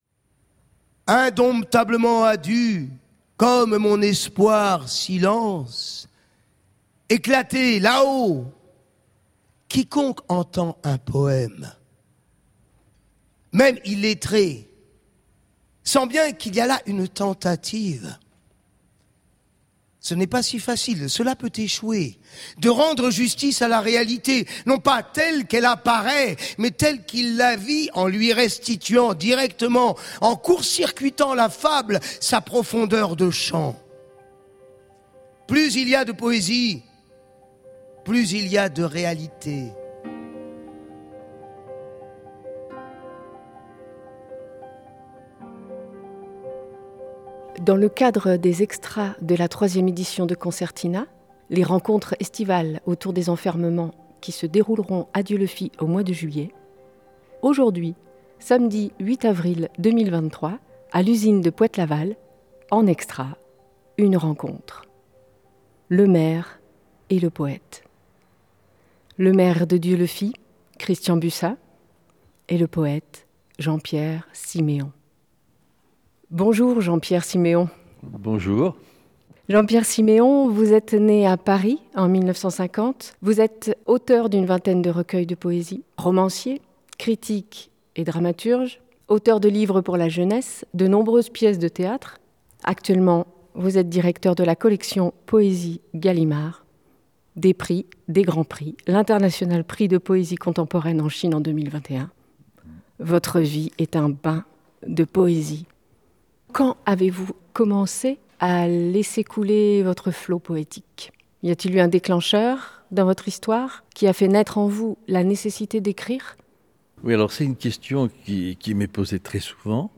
Interview
Rencontre avec le poète Jean-Pierre Siméon dans le cadre des « extras » de la 3ème édition de Concertina, les rencontres estivales autour des enfermements qui se dérouleront à Dieulefit au mois de juillet 2023.
Cet entretien a été réalisé à l’Usine de Poët-Laval, samedi 8 avril, avant une discussion publique entre Jean-Pierre Siméon et Christian Bussat, maire de Dieulefit.